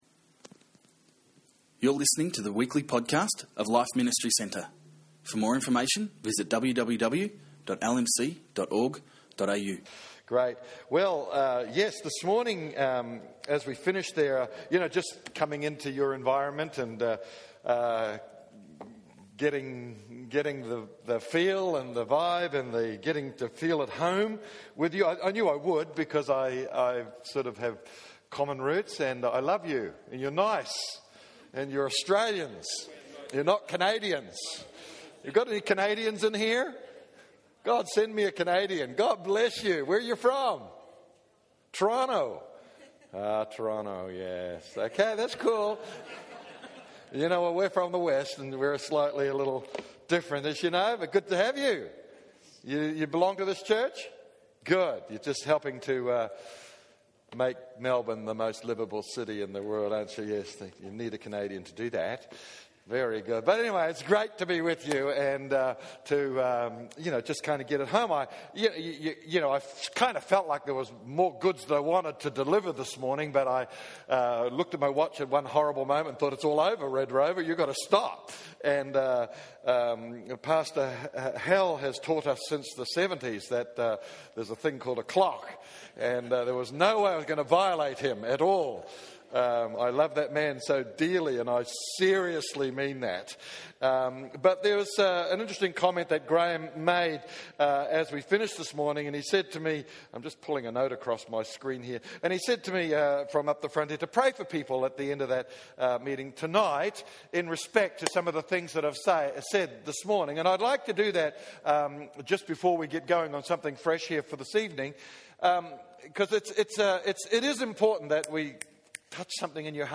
(Includes prophesies given.)